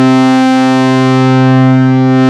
SYN MMS2.wav